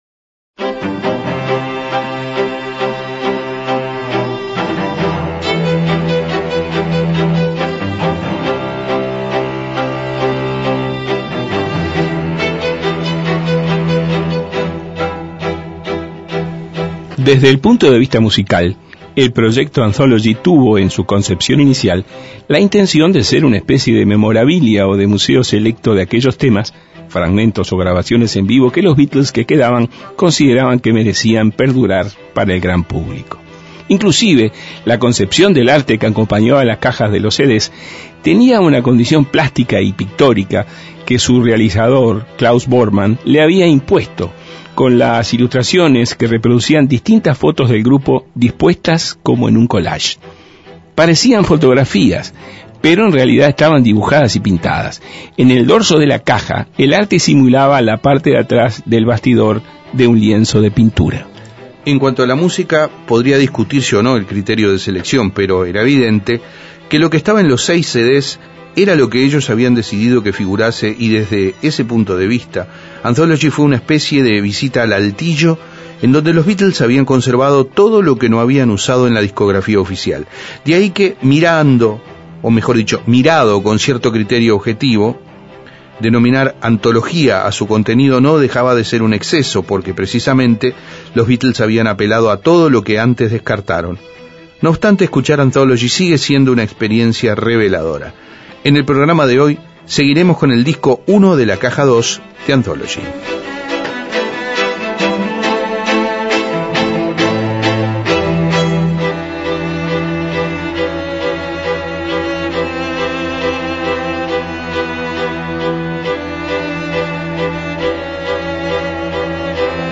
Las maquetas de entrecasa y los arreglos que no fueron.